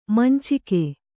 ♪ mancike